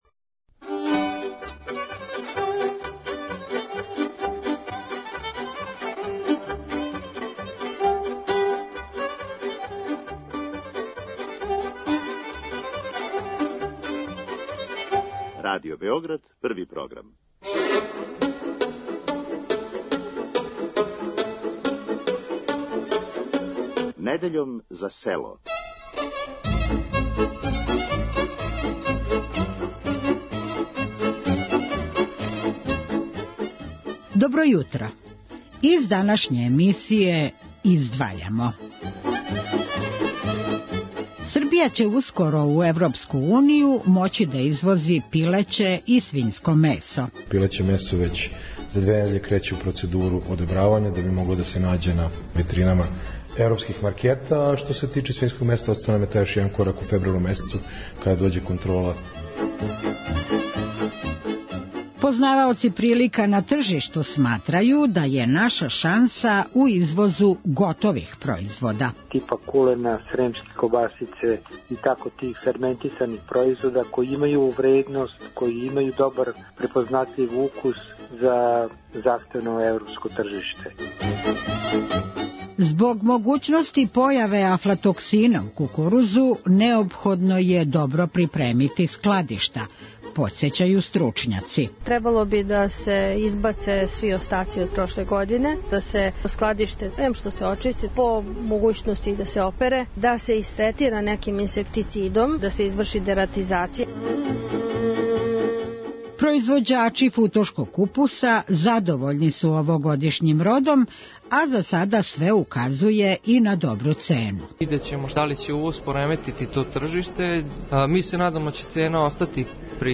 О томе како сачувати кукуруз од могуће заразе разговарали смо са саветодавком за заштиту биља